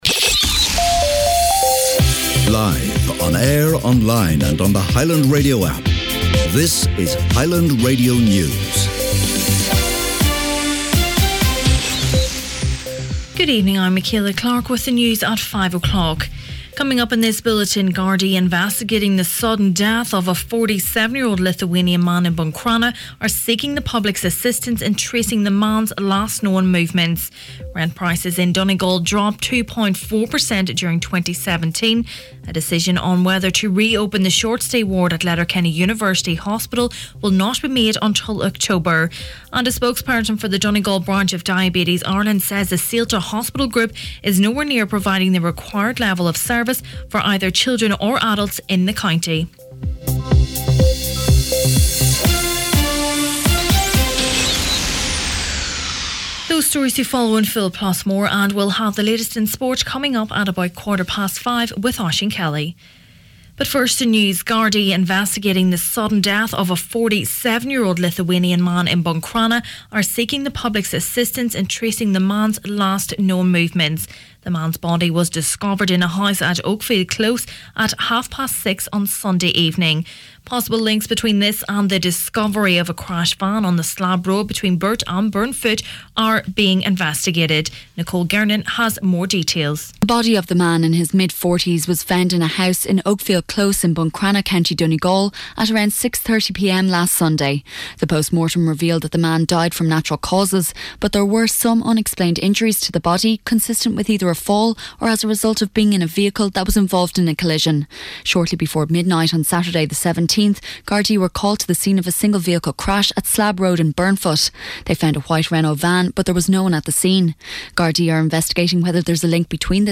Main Evening News, Sport and Obituaries Wednesday 21st March